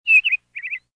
SZ_TC_bird3.ogg